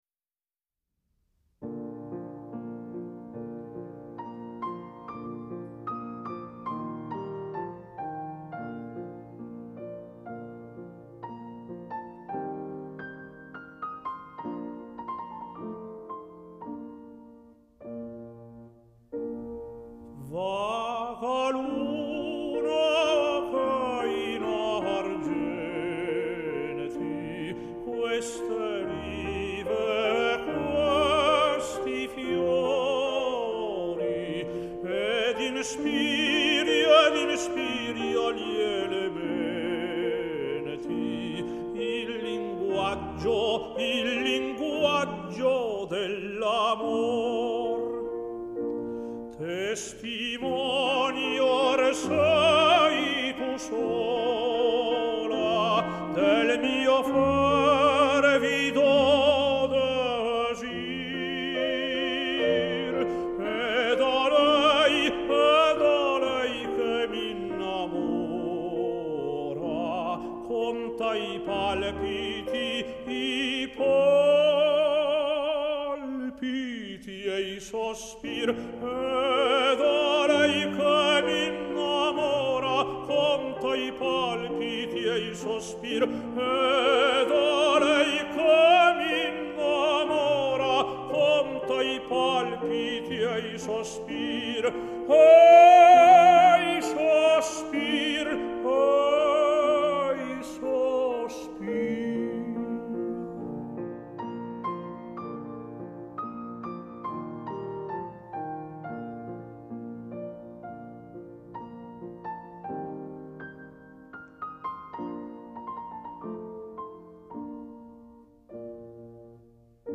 Сегодня исполняется 87 лет великому итальянскому тенору Карло Бергонци!